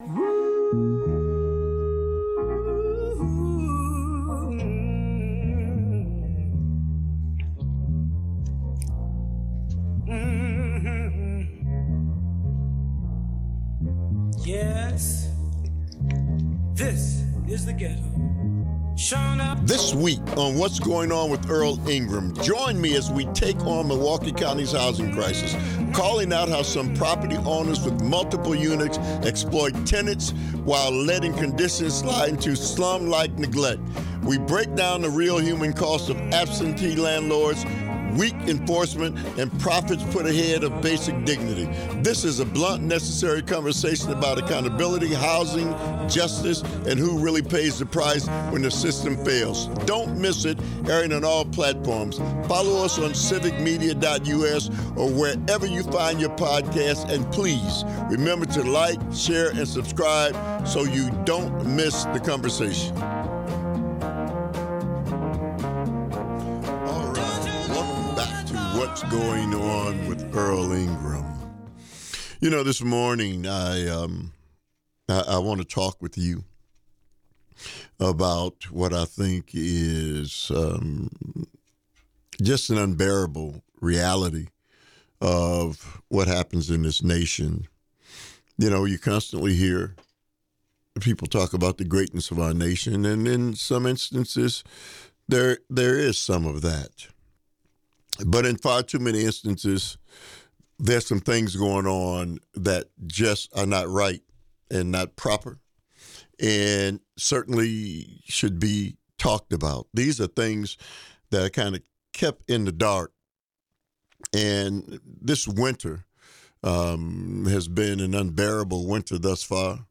Guest: The listening Audience.